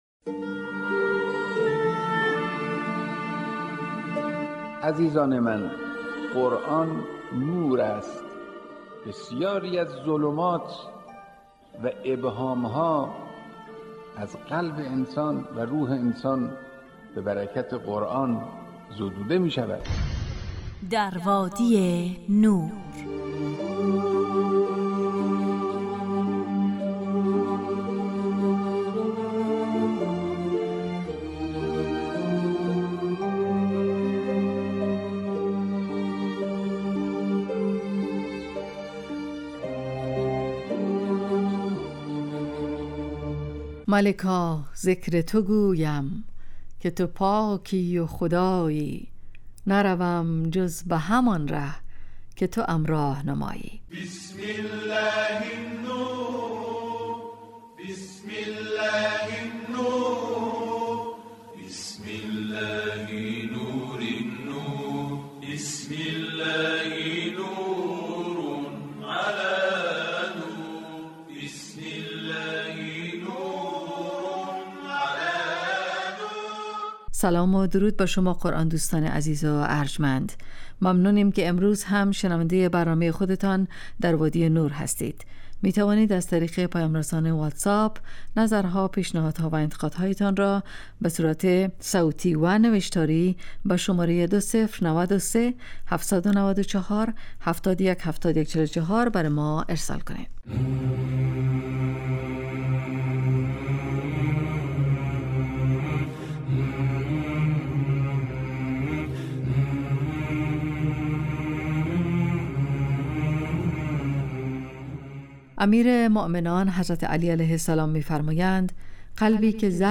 طلایه داران تلاوت، ایستگاه تلاوت